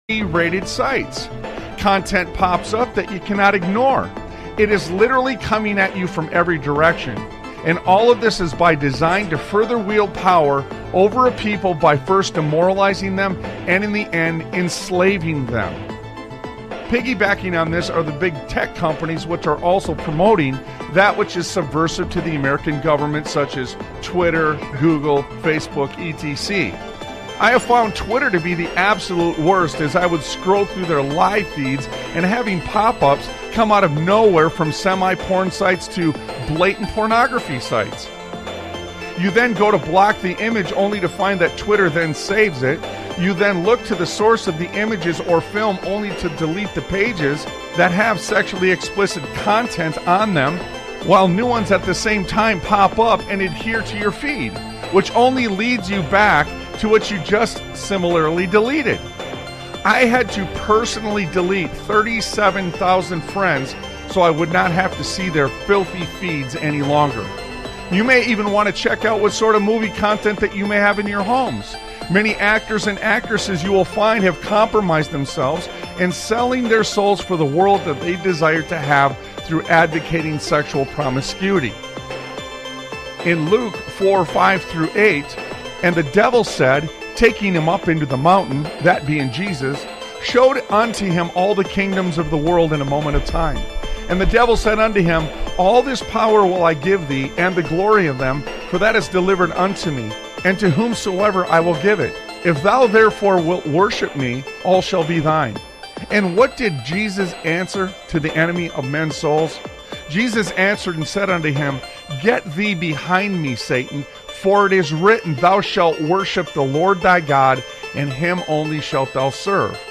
Talk Show Episode, Audio Podcast, Sons of Liberty Radio and A Week Of Building Up on , show guests , about A Week Of Building Up, categorized as Education,History,Military,News,Politics & Government,Religion,Christianity,Society and Culture,Theory & Conspiracy